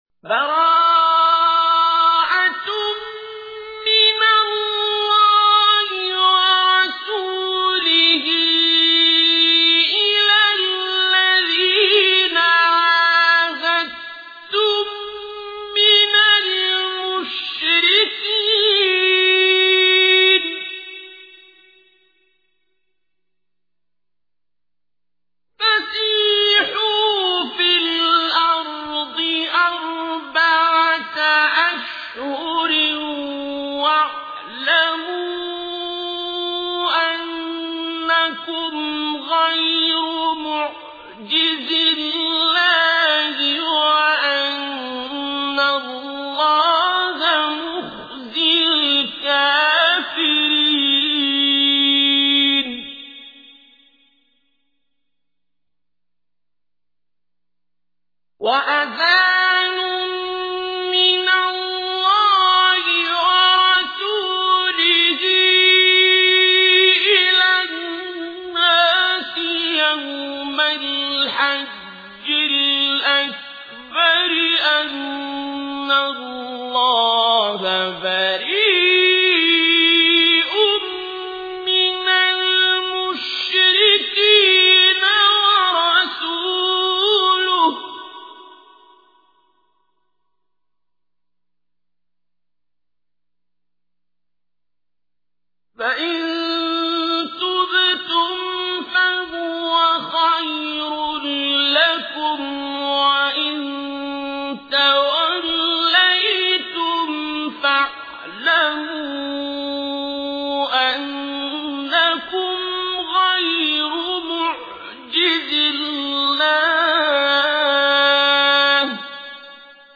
Listen online and download beautiful recitation/tilawat of Surah At-Tawbah recited by Qari Abdul Basit As Samad.